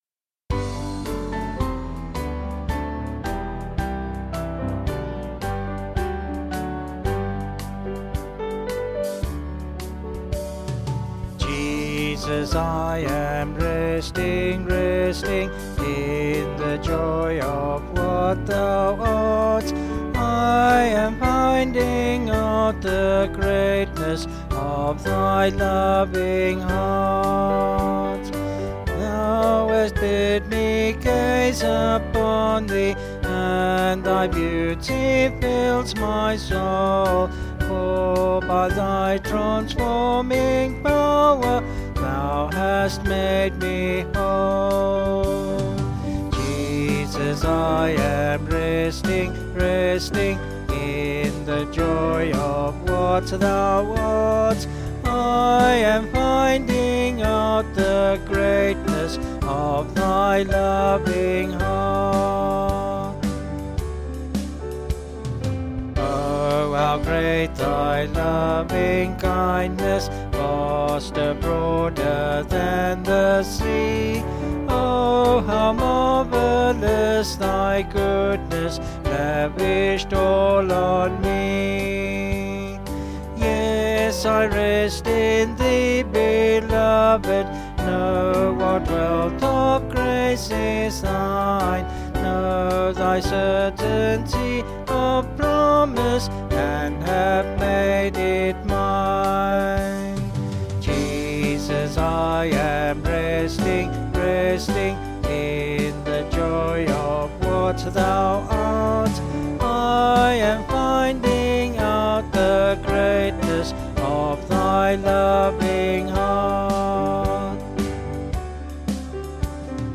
Vocals and Band   266kb Sung Lyrics